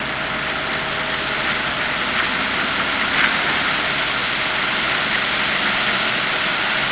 Car Wet Tires In Dirt